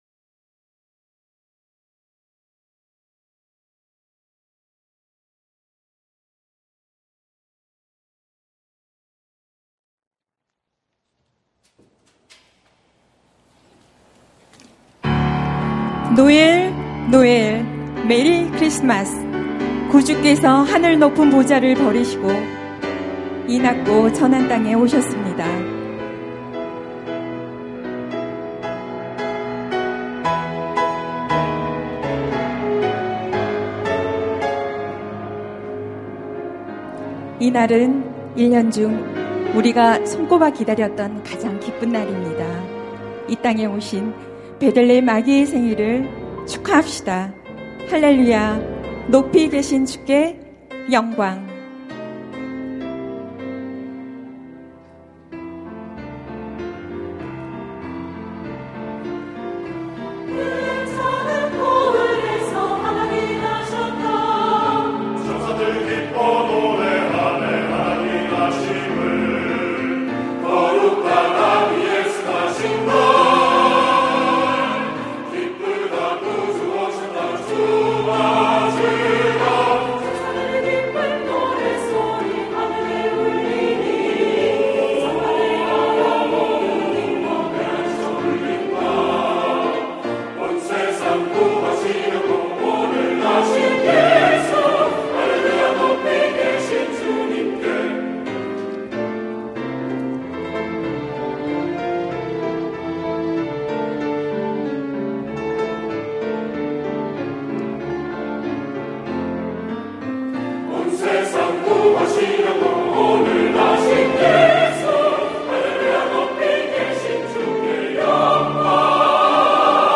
성탄절 칸타타